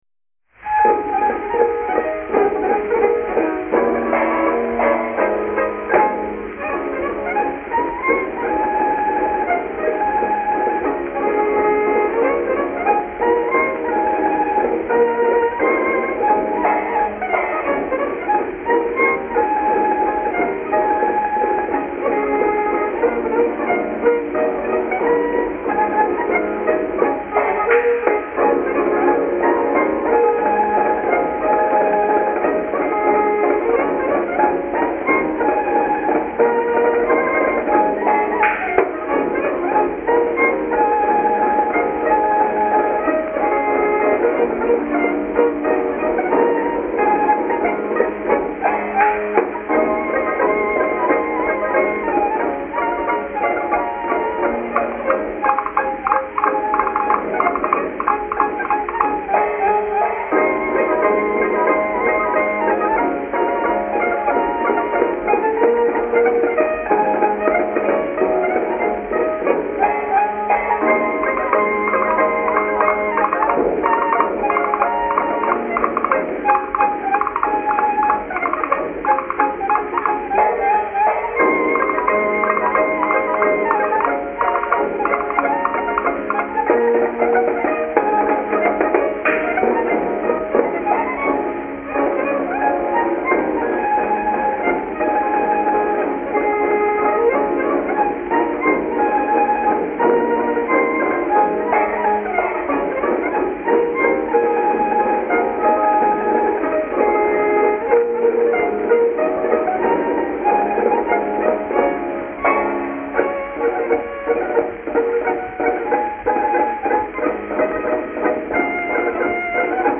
Berlin, c. May 1921